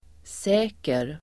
Uttal: [s'ä:ker]